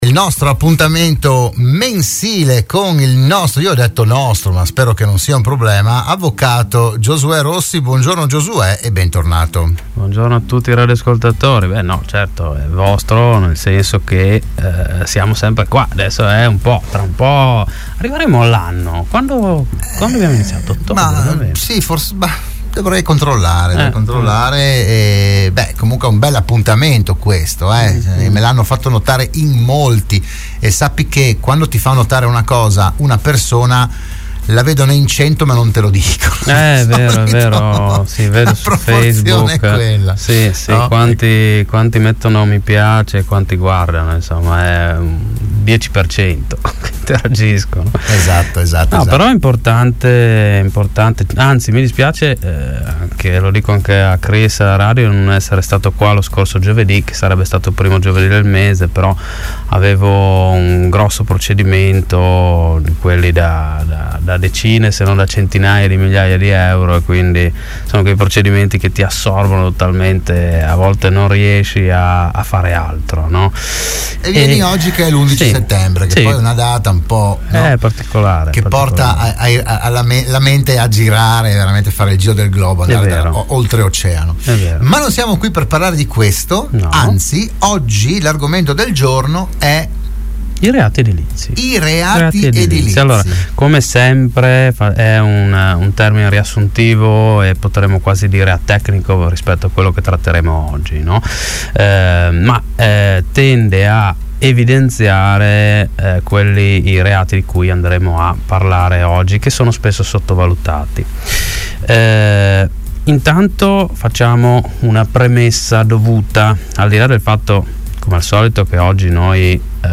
in onda all’interno di “RadioAttiva” su Radio Studio Nord